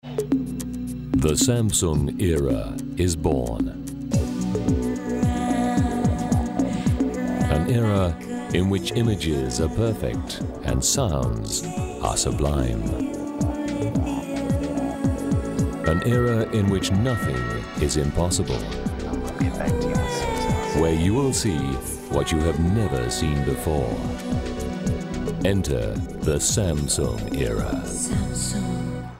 RP / Trans-Atlantic. Versatile - deep, smouldering, authoritative to warm, reassuring, ironic, Promos, Ads, Documentaries, Corporates.